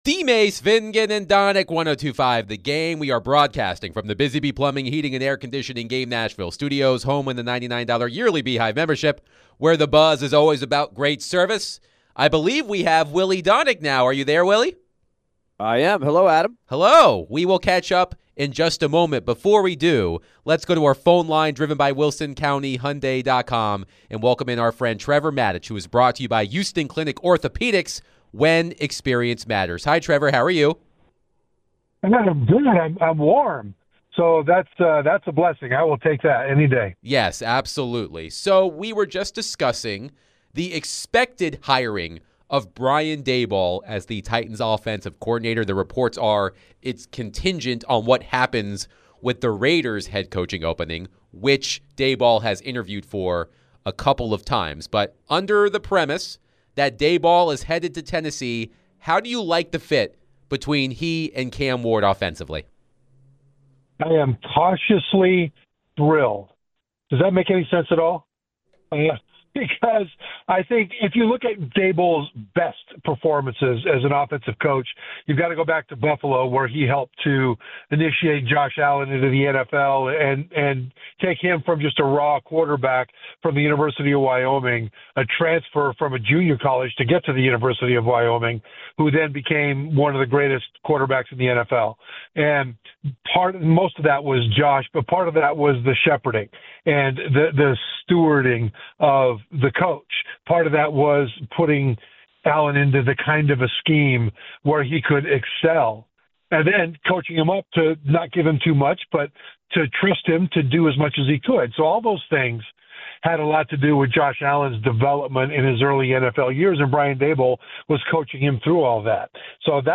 ESPN NFL Analyst Trevor Matich joined DVD to discuss all things Titans, Brian Daboll, Super Bowl and more.